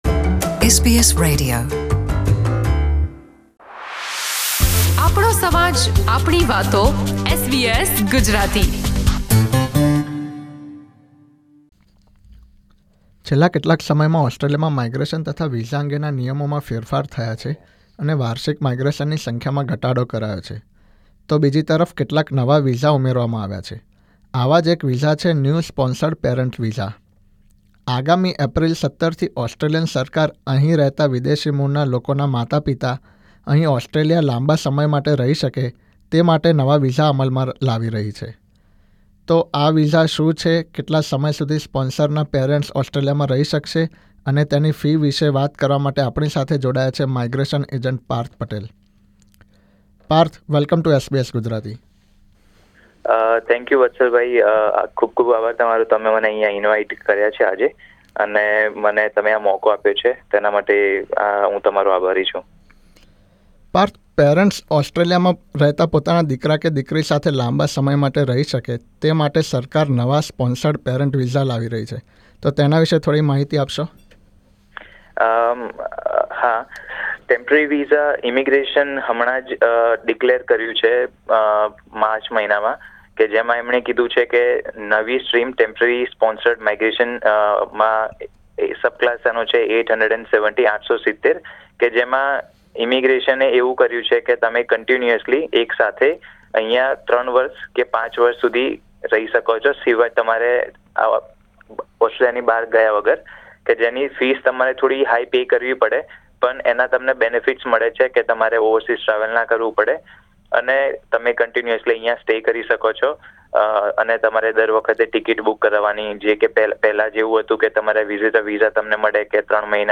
SBS Gujarati સાથે વિશેષ વાતચીત કરી હતી.